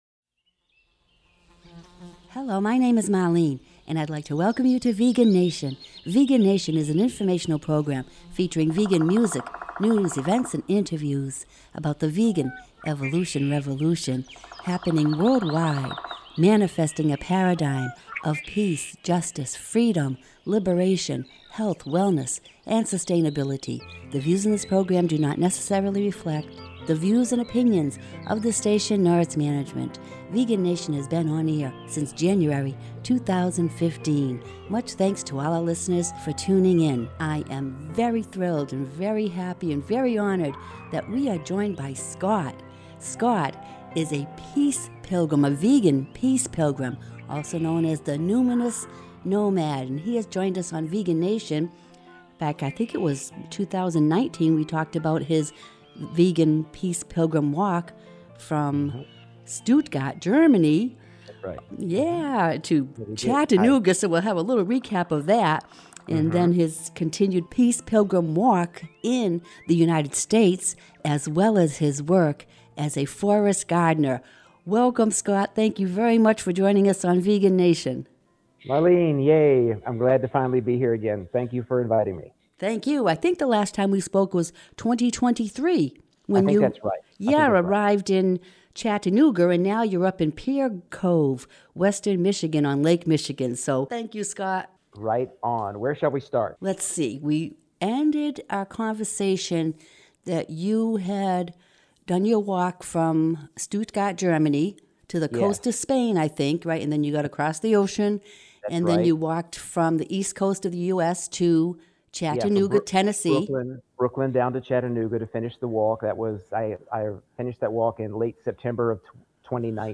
Vegan Nation is a 1/2-hour radio show featuring vegan music, news, events and interviews.